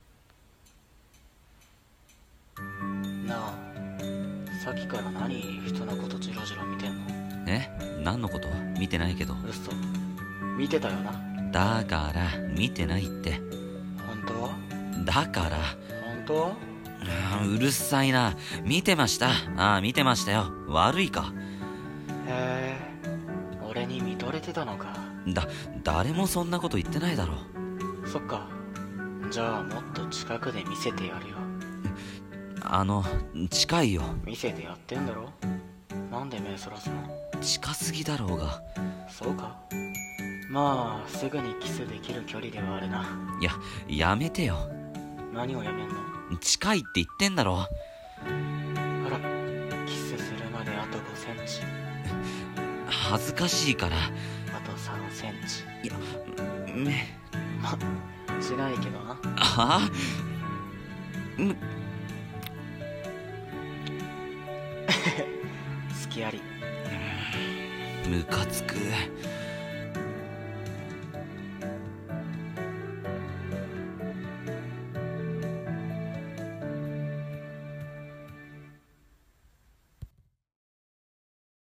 【声劇台本】あと何cm？［台本］BL